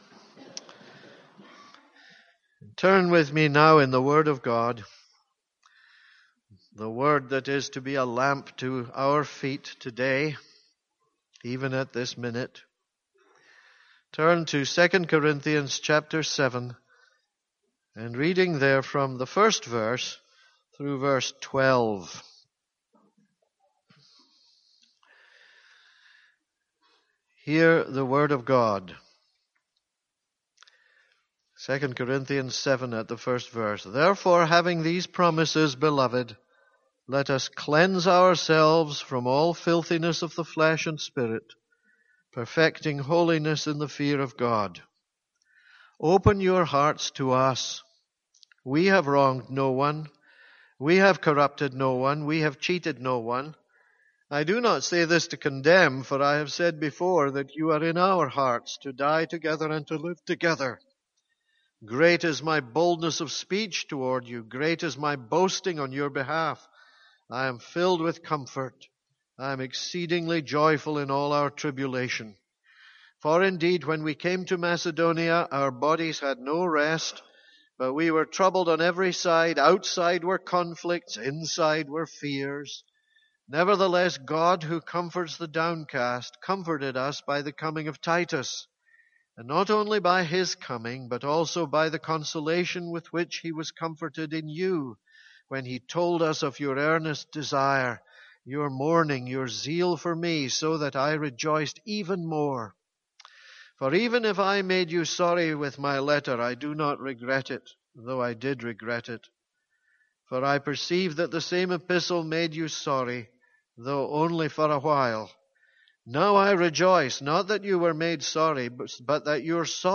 This is a sermon on 2 Corinthians 7:5-12.